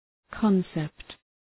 Προφορά
{‘kɒnsept}